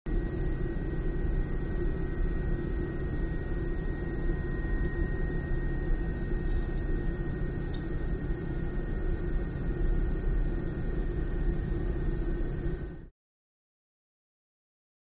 Centrifuge; Lab Ambience With Machine Hum